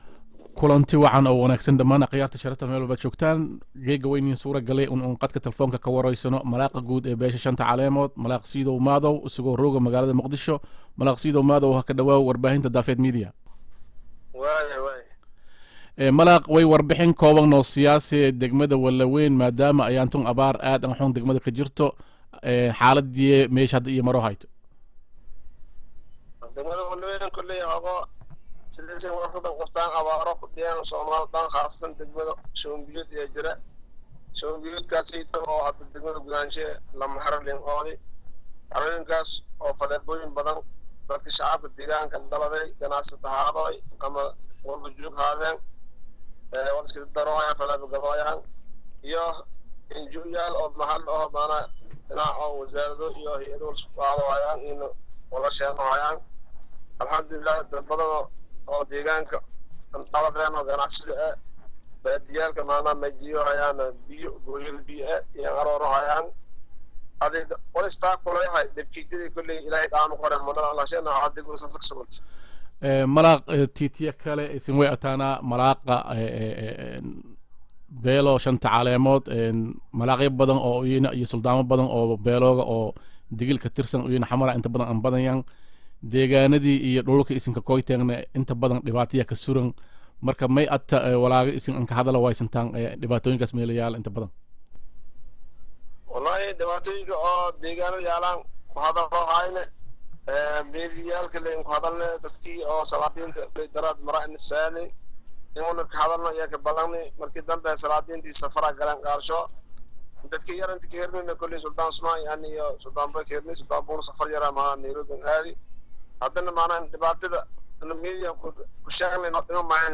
qadka talfoonku wareysi